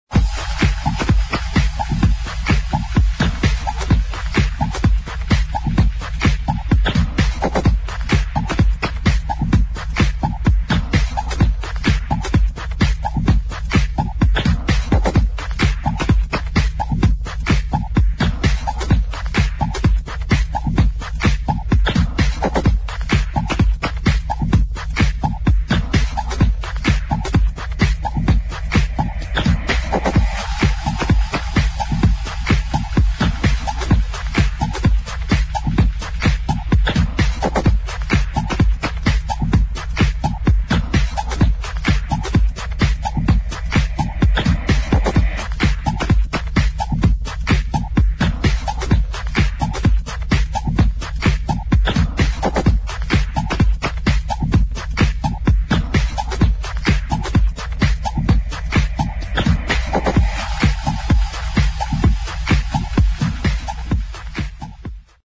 [ HOUSE | TECH HOUSE ]